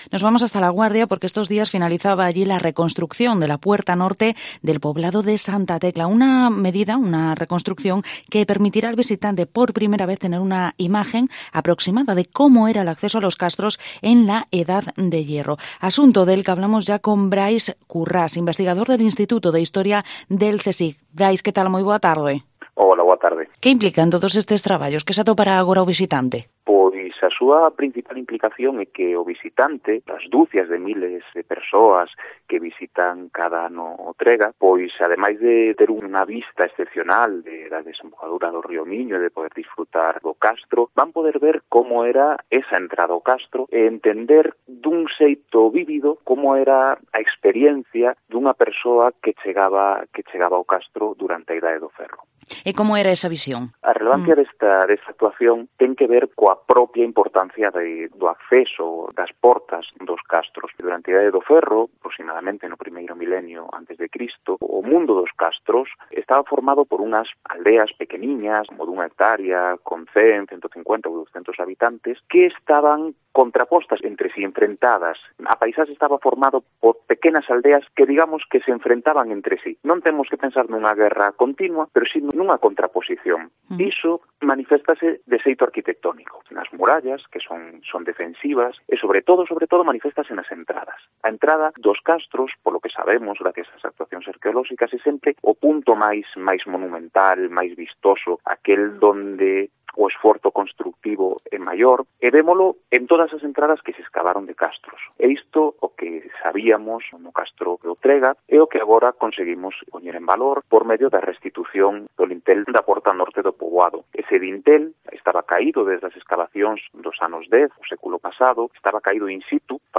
Entrevista expertos rehabilitación Castro Santa Tecla A Guarda